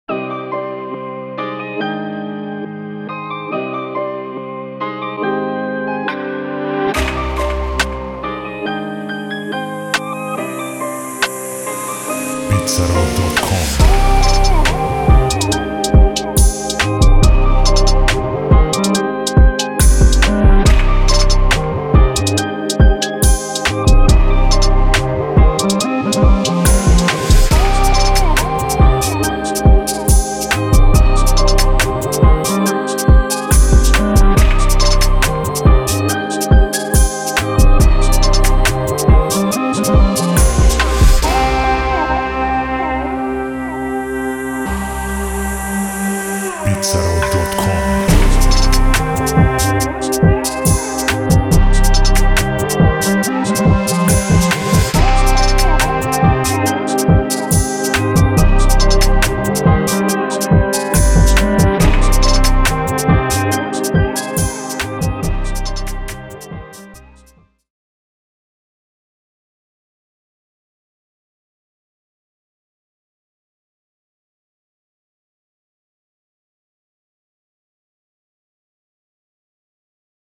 دسته و ژانر: Drill
سبک و استایل: گنگ،دیس
سرعت و تمپو: 130 BPM